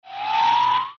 grabDrone.mp3